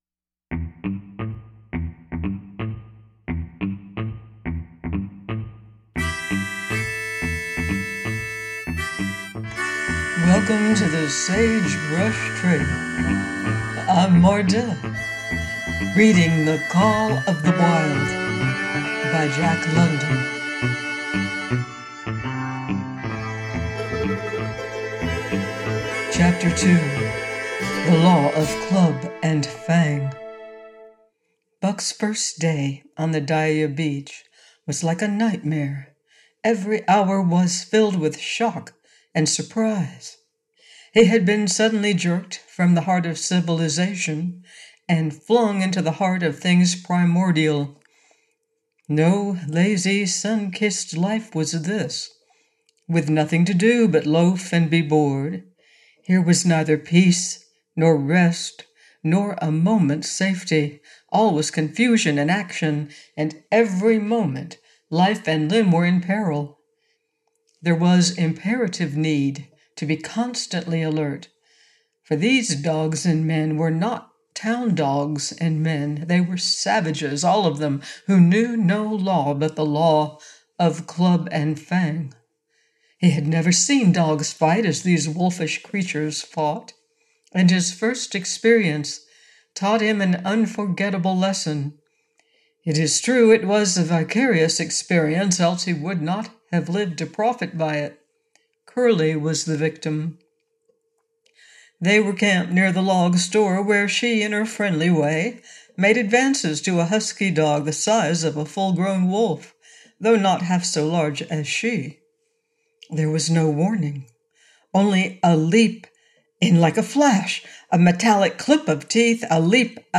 The Call Of The Wild: by Jack London - AUDIOBOOK